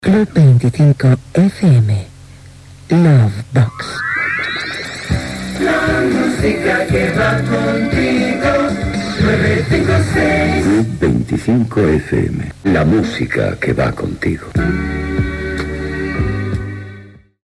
Indicatiu de l'emissora i nom del programa
FM